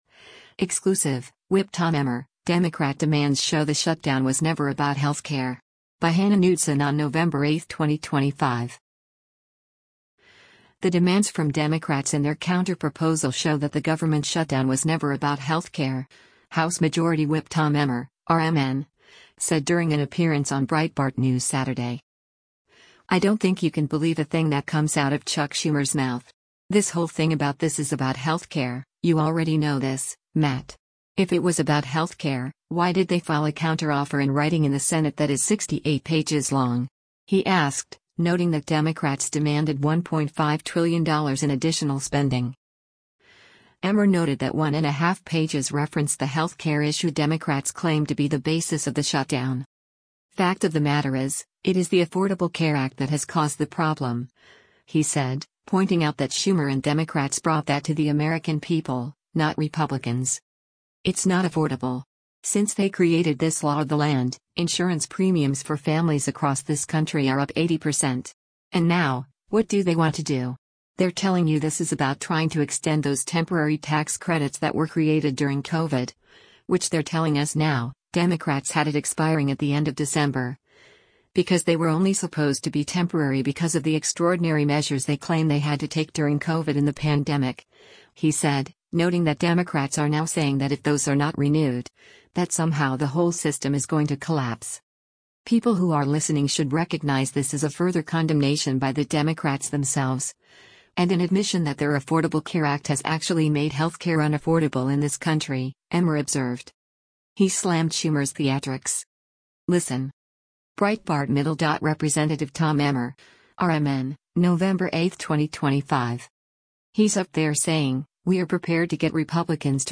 The demands from Democrats in their counterproposal show that the government shutdown was never about health care, House Majority Whip Tom Emmer (R-MN) said during an appearance on Breitbart News Saturday.